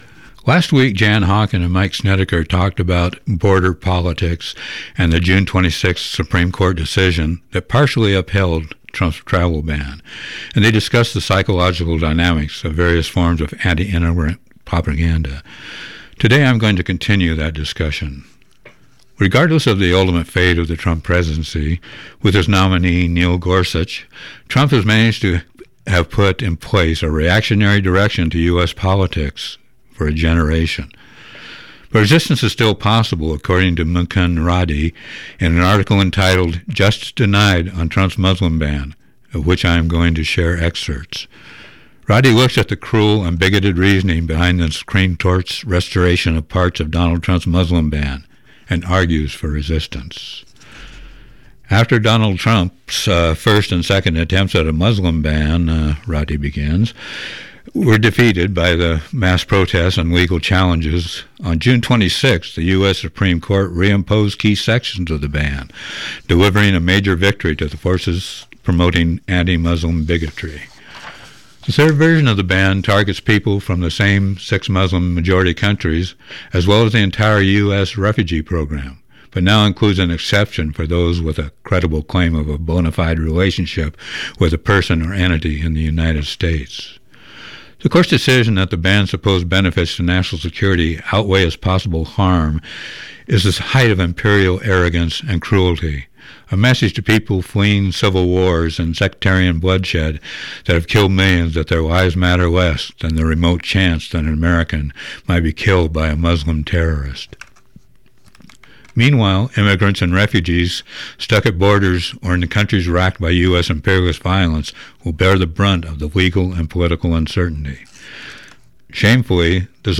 Socialist Worker reading on Trump's travel ban